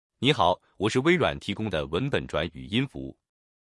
Edge-TTS是微软提供的在线文本转自然语音，支持多种语言和声音，转换速度快，语音自然无机械感。
同一段文字分别使用这几种声音转成语音文件对比：